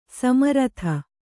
♪ sama ratha